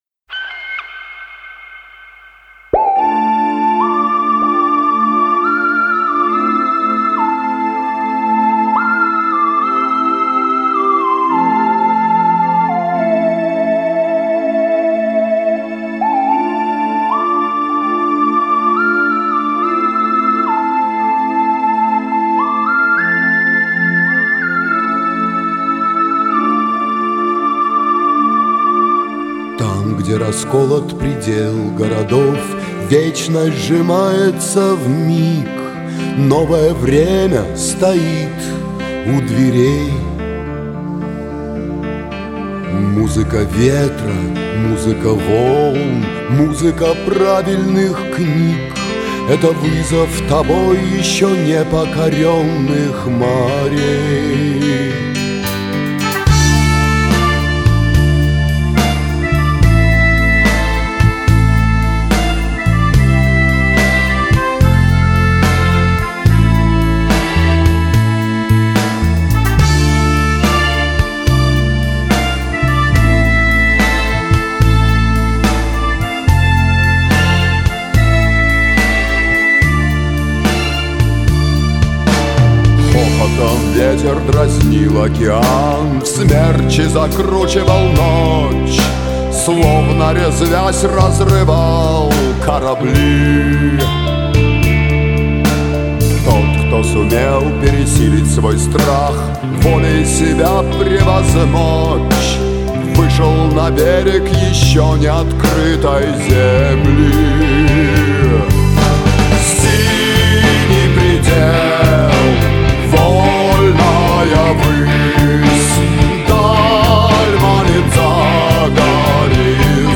Главная / Computer & mobile / Мелодии / Патриотические песни